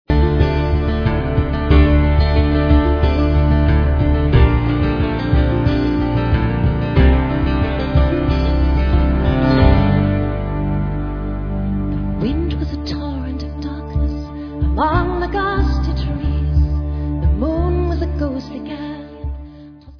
Celtic-worldbeat-folk-pop//special box